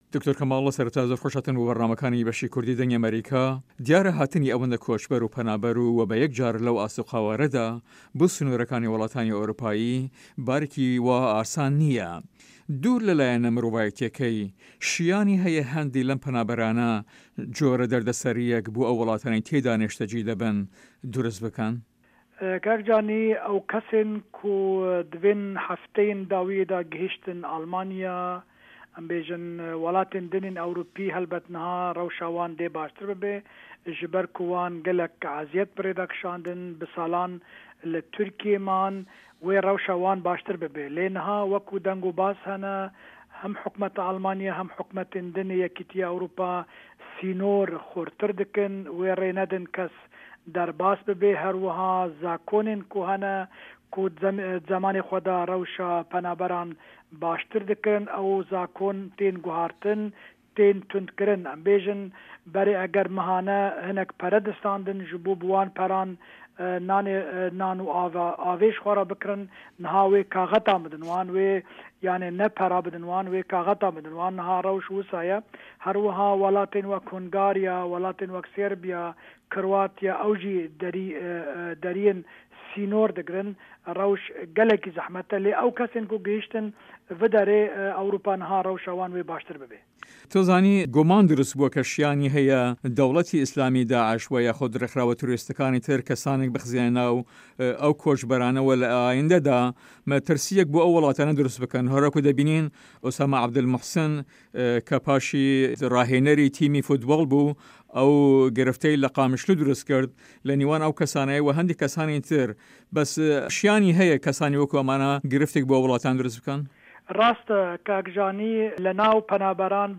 by دەنگی ئەمەریکا | Dengî Amerîka | VOA Kurdish